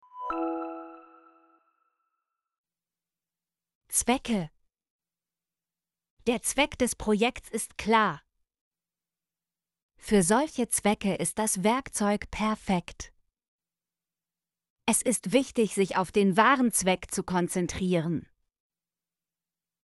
zwecke - Example Sentences & Pronunciation, German Frequency List